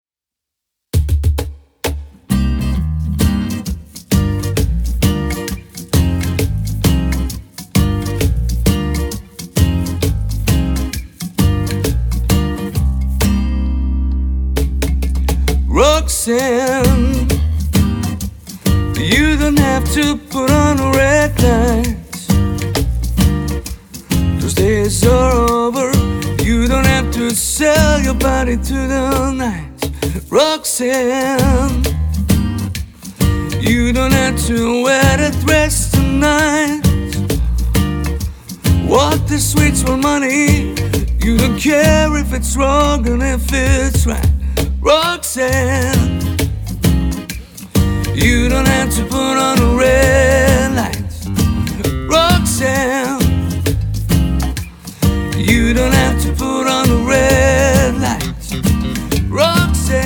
cajon & drums